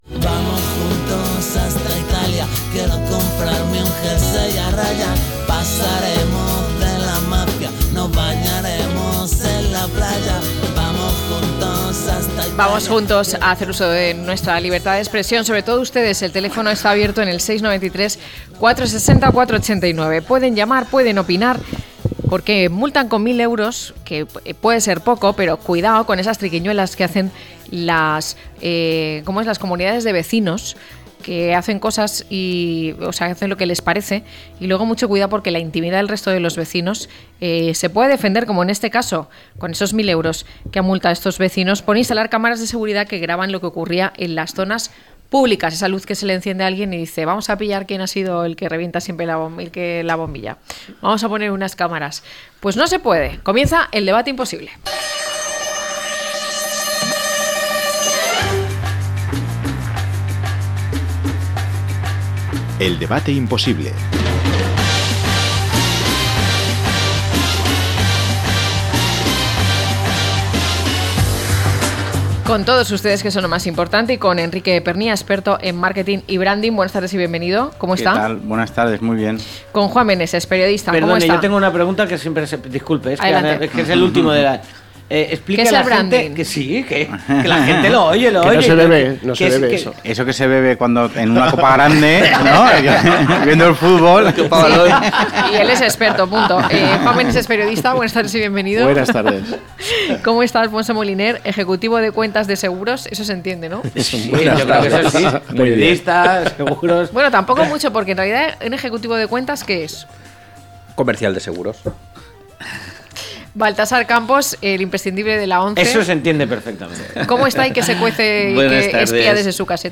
Las cámaras de seguridad que nos graban, a debate - La tarde con Marina
0711-LTCM-DEBATE.mp3